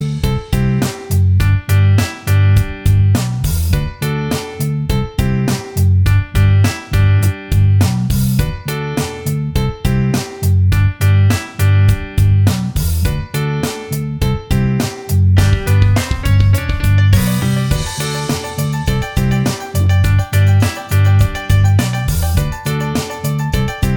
End Backing Vocals Removed Pop (2010s) 4:54 Buy £1.50